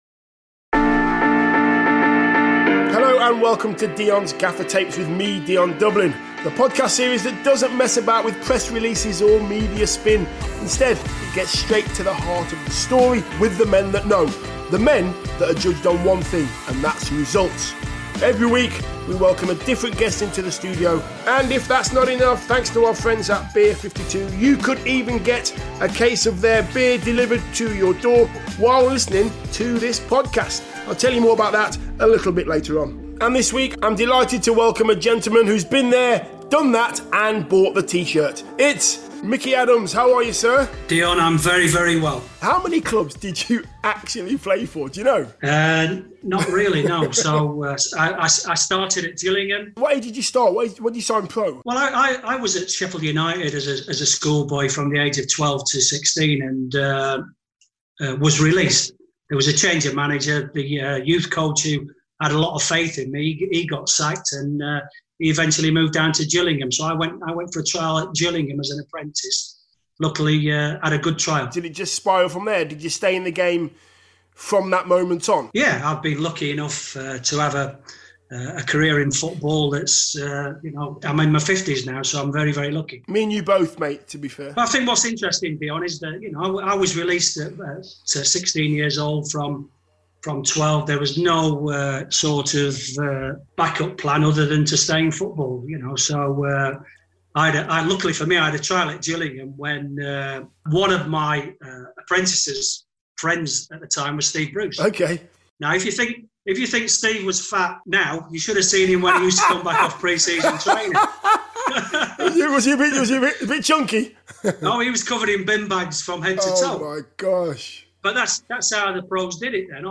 This episode was recorded during remotely during the pandemic.